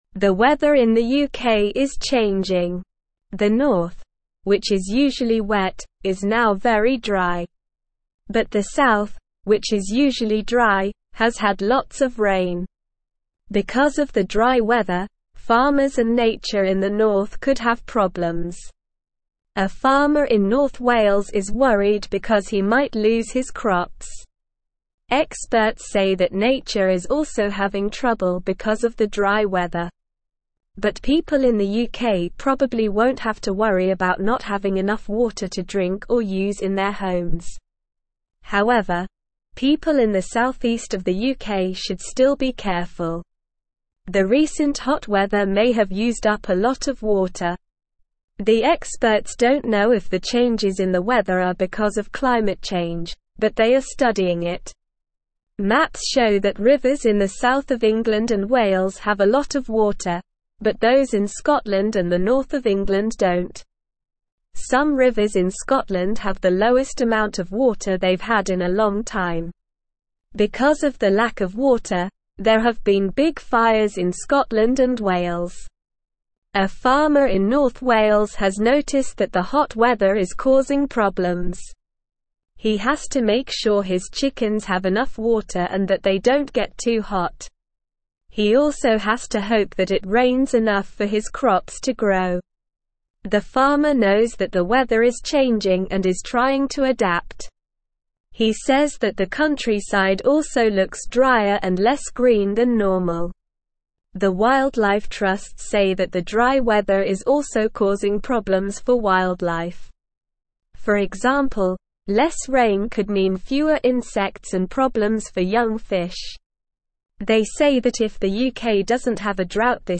Slow
English-Newsroom-Beginner-SLOW-Reading-UK-Weather-Changes-Worry-Farmers-and-Nature.mp3